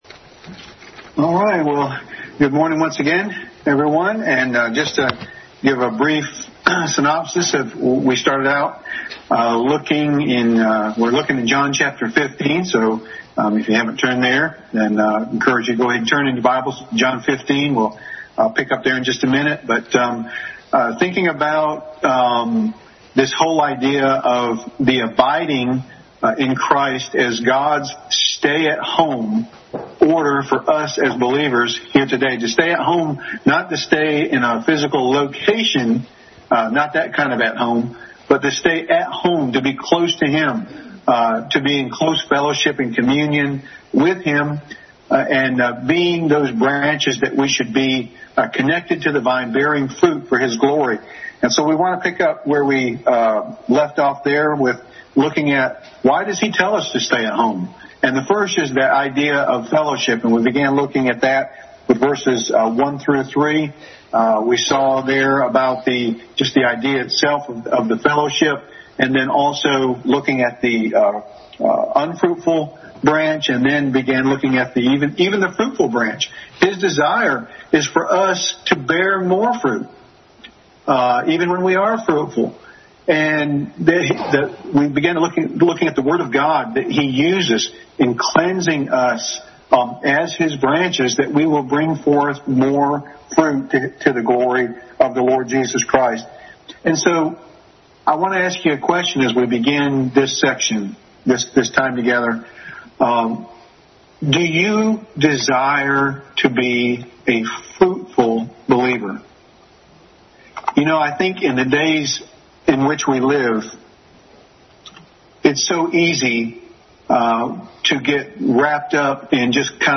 Family Bible Hour message.